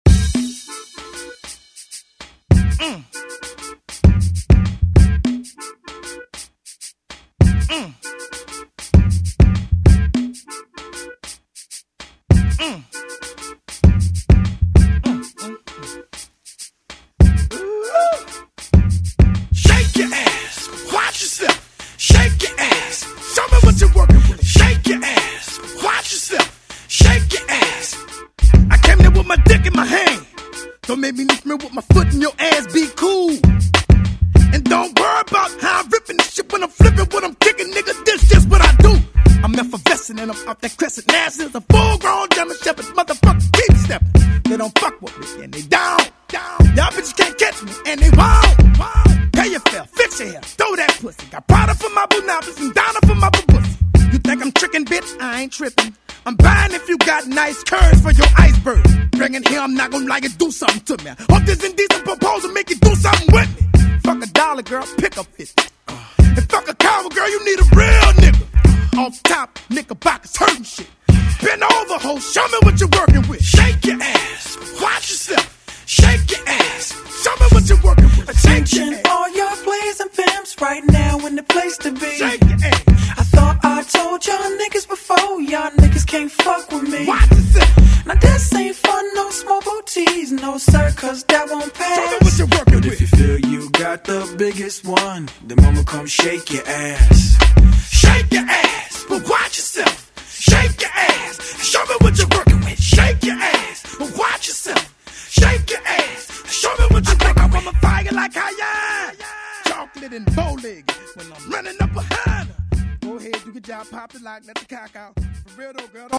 BPM: 98 Time